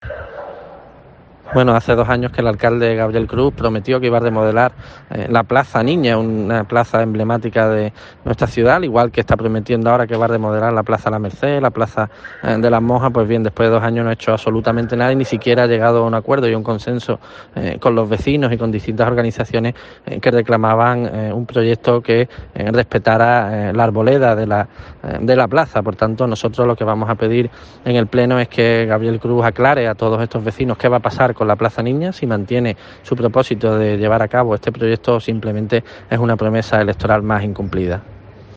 Guillermo García de Longoria, portavoz de Cs en el Ayto Huelva